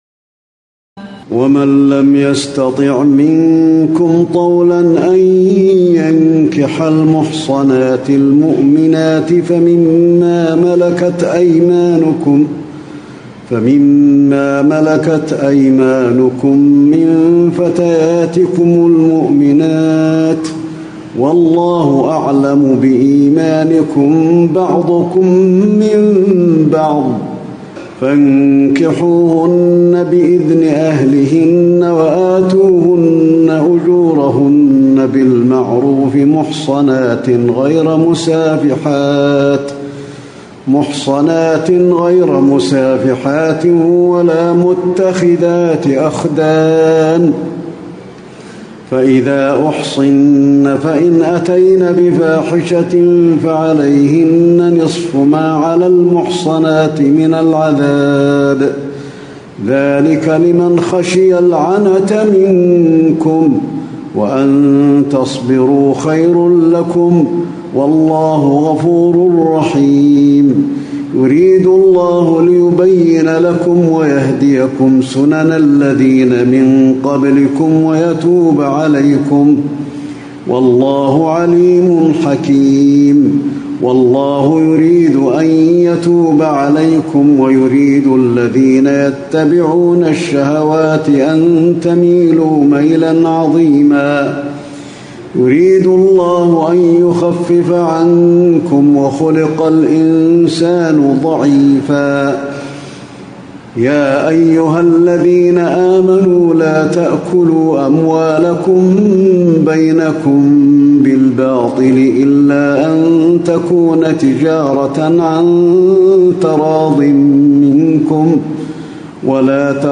تراويح الليلة الخامسة رمضان 1437هـ من سورة النساء (25-87) Taraweeh 5 st night Ramadan 1437H from Surah An-Nisaa > تراويح الحرم النبوي عام 1437 🕌 > التراويح - تلاوات الحرمين